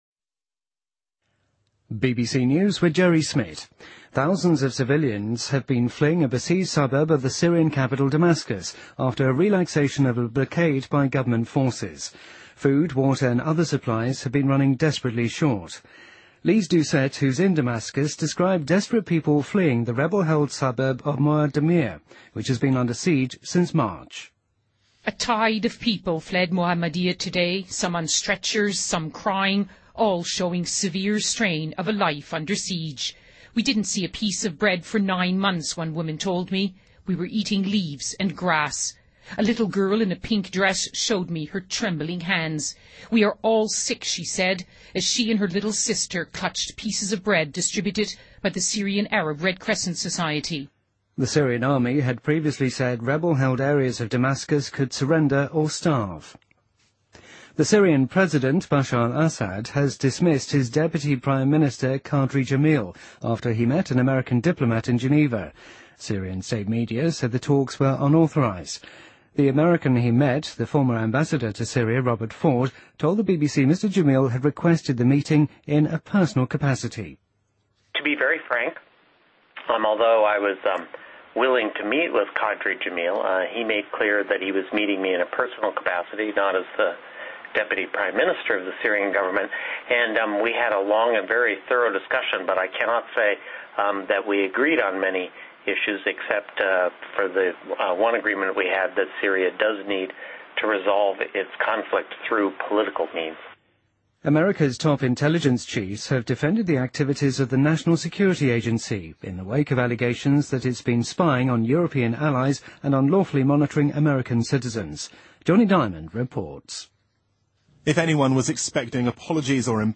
BBC news,联合国大会压倒性通过决议谴责美国对古巴的禁运